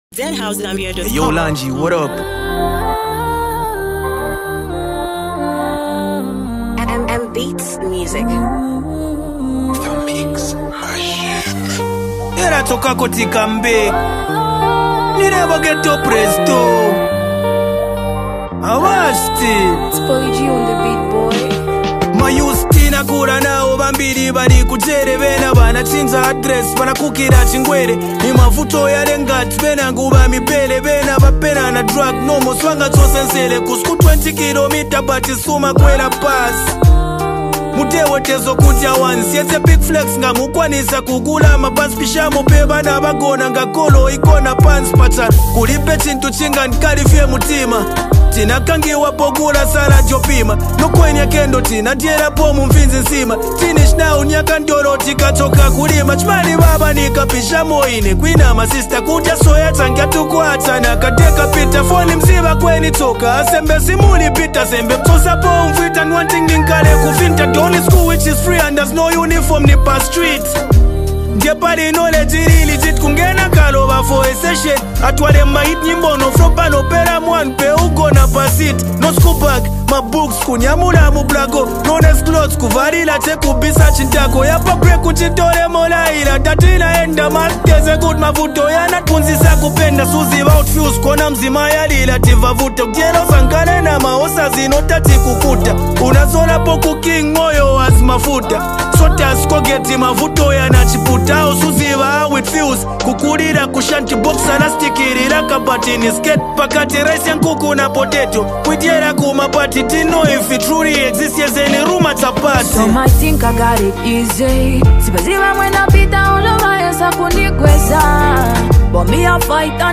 emotional new single